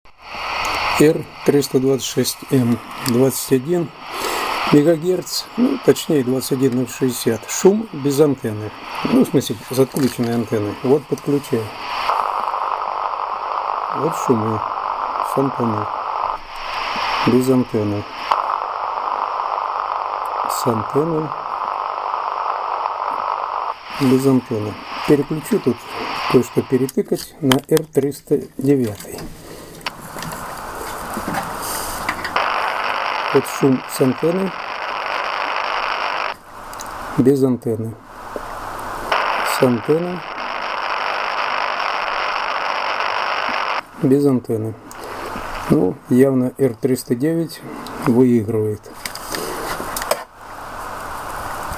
И аудио, где можно оценить шумы Р-326М и Р-309.
Кому интересно, представлю аудио, сравнения шума Р-326М и Р-309.